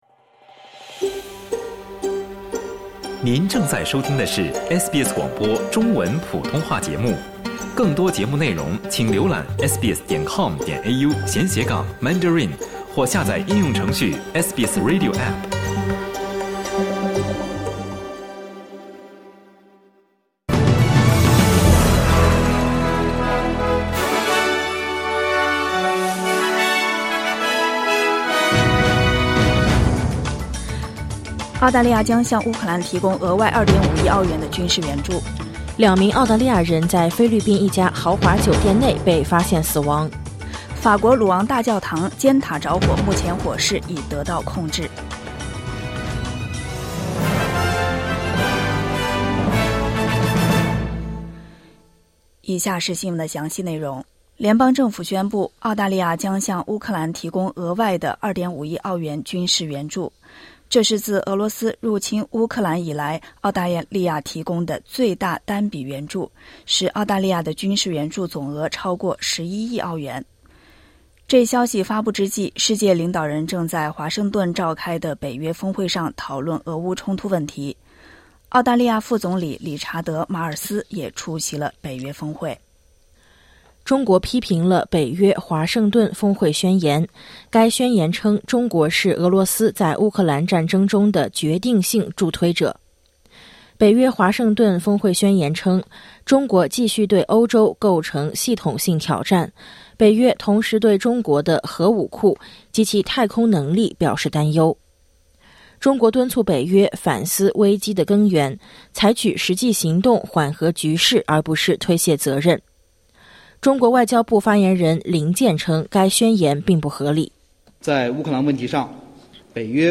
SBS早新闻（2024年7月12日）